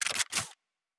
Weapon 09 Reload 3.wav